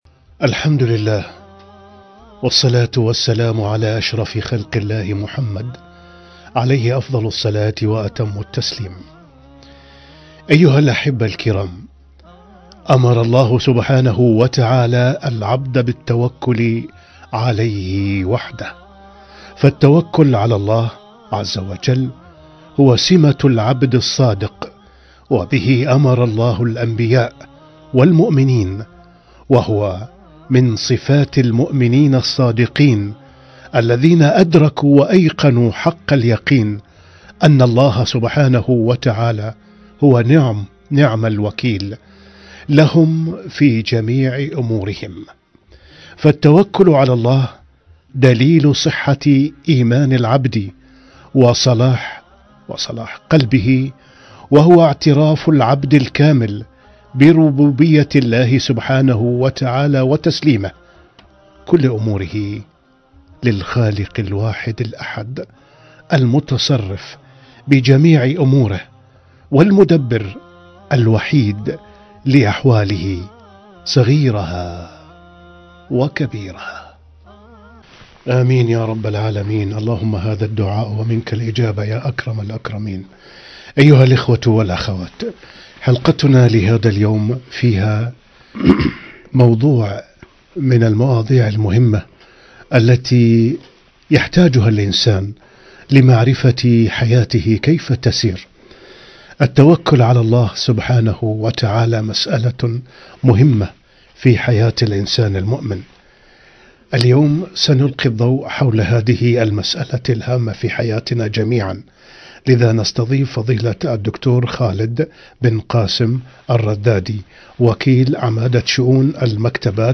التوكل على الله - لـقـاء عبر برنامج واحة المستمعين